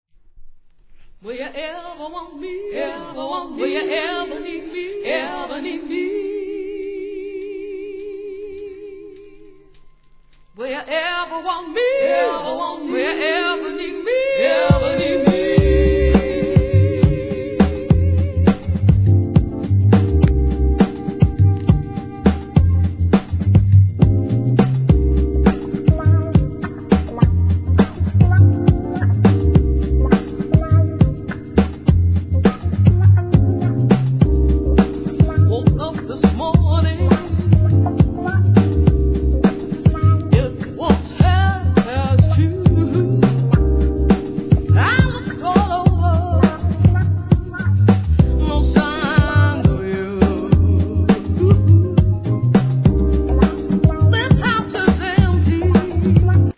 HIP HOP/R&B
インディー洒落オツUK R&B